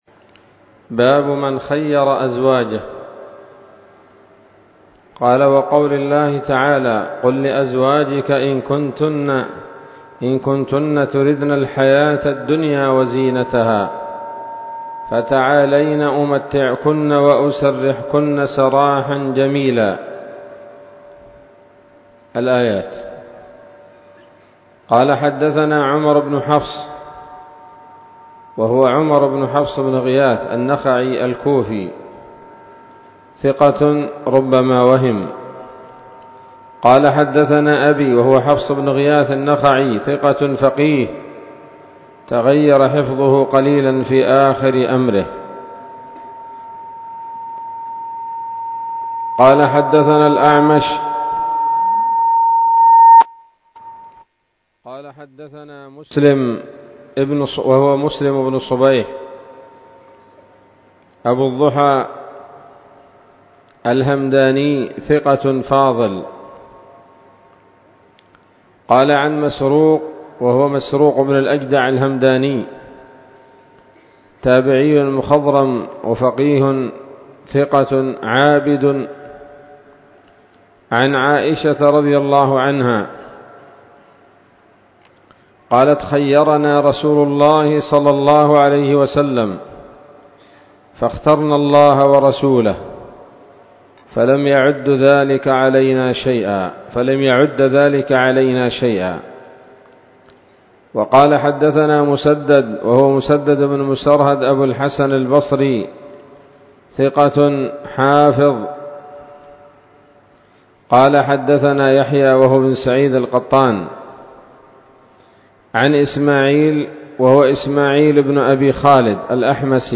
الدروس العلمية شروح الحديث صحيح الإمام البخاري كتاب الطلاق من صحيح البخاري
الدرس الخامس من كتاب الطلاق من صحيح الإمام البخاري